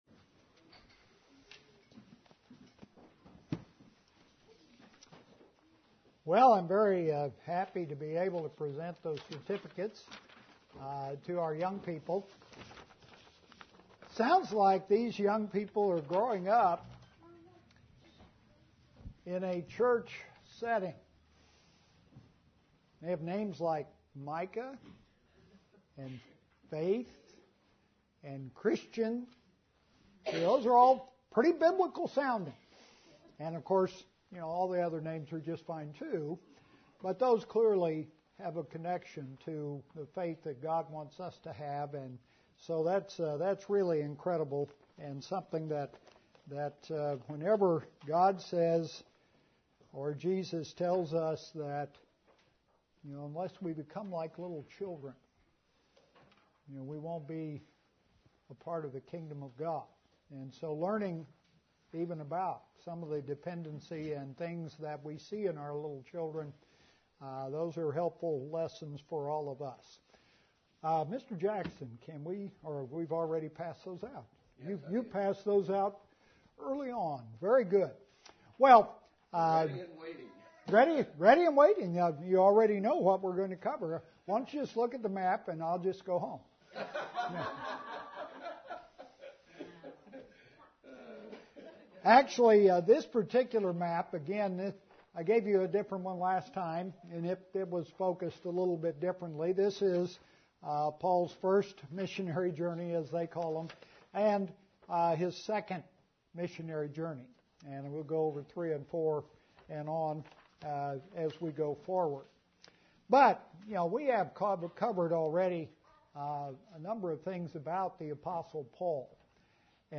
Part 2 of sermon series on the Book of Acts